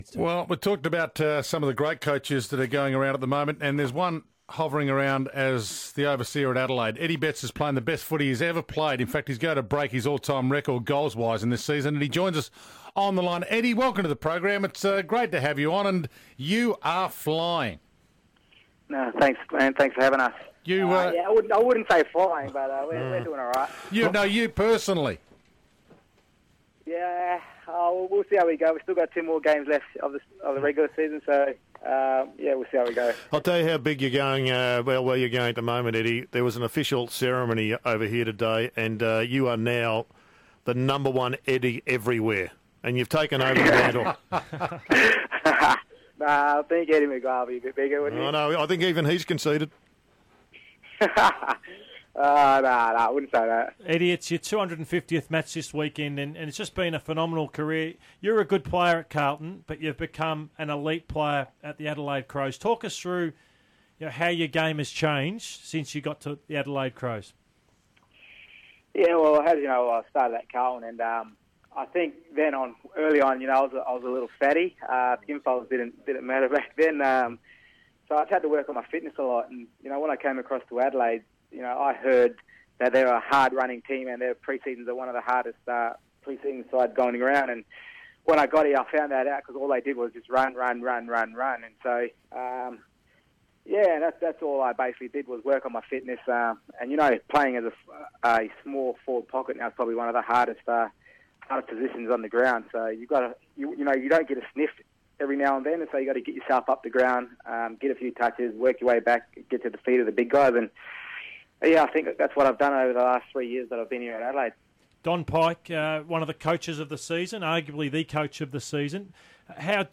Livewire forward Eddie Betts spoke on Melbourne radio after Adelaide's win over Fremantle